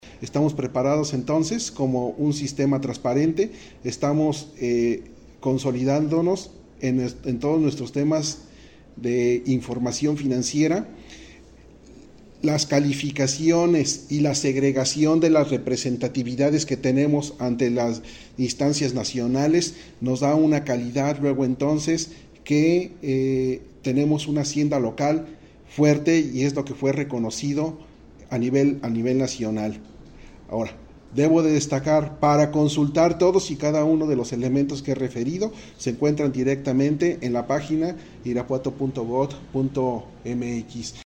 AudioBoletines
Miguel ángel Fonseca, tesorero